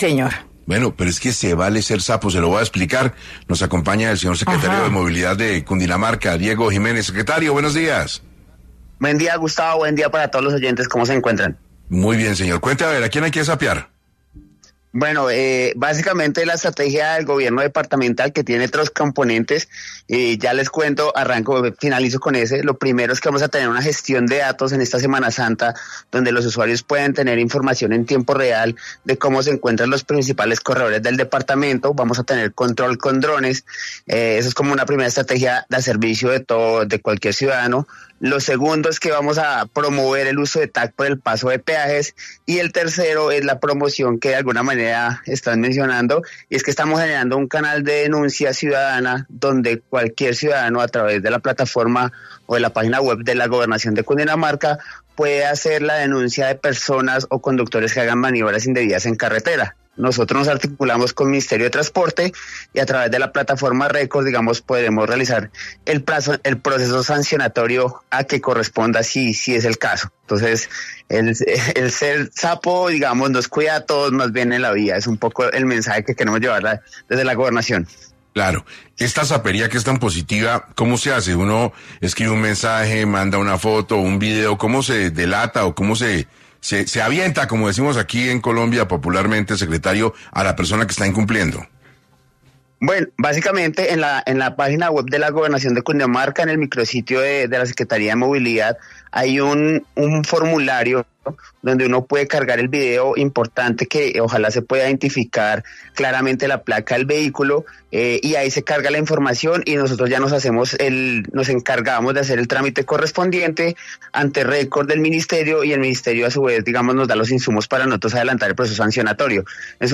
El Secretario de Movilidad de Cundinamarca Diego Jiménez detalló en 6AM de Caracol Radio cómo funciona la nueva plataforma para que los colombianos denuncien los malos comportamientos en las vías que ponen en peligro a los viajeros.